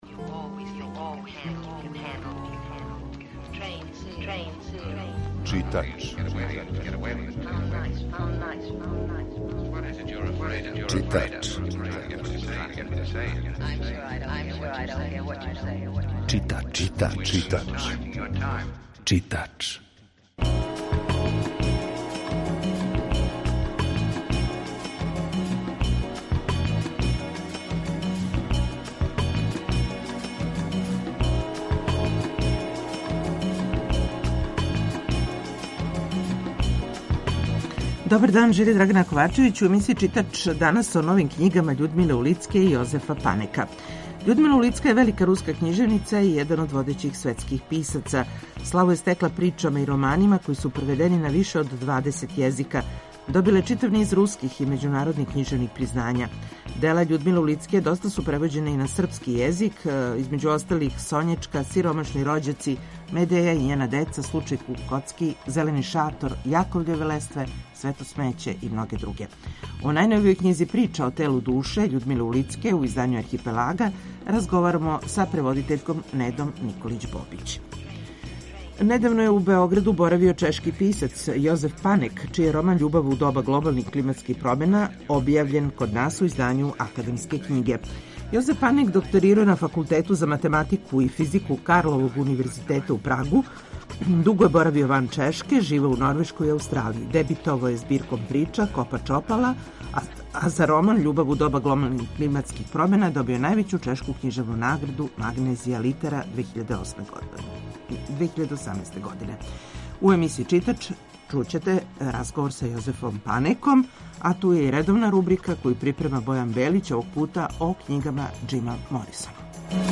Емисија је колажног типа